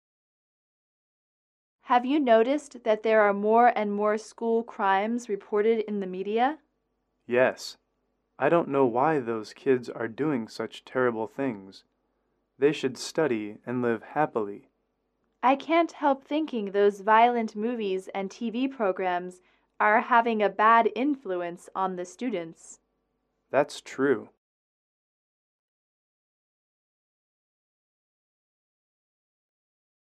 英语主题情景短对话23-4：校园犯罪（MP3）